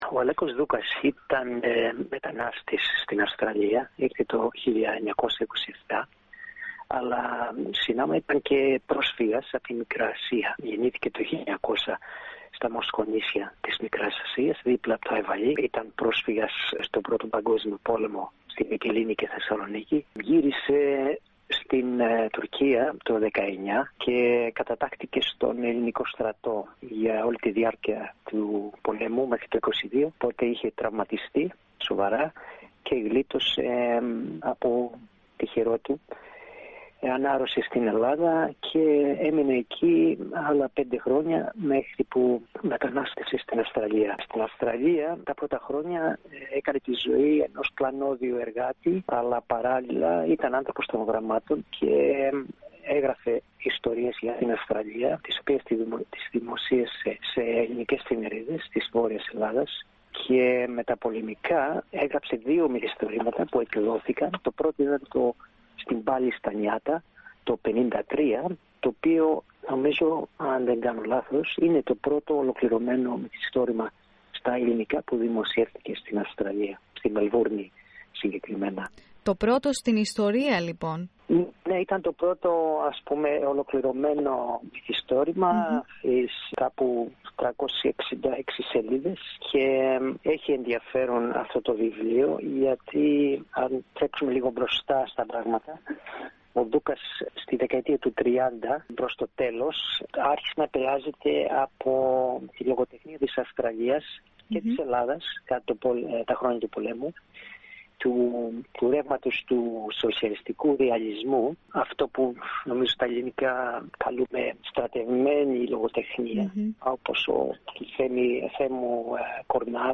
Διαβαστε Οι ιστορίες των πρώτων Ελλήνων μεταναστών της Αυστραλίας Πατήστε Play στο Podcast που συνοδεύει την κεντρική φωτογραφία για να ακούσετε τη συνέντευξη.